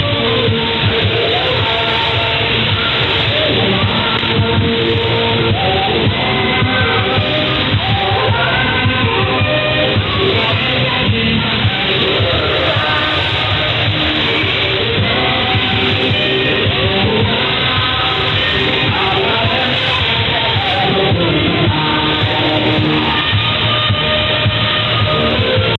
Most of the intrusions on amateur radio bands in this mode of transmission are carried out by broadcasting stations.
A3E — BC station VoBM 1 (ERI) on 7140 kHz CF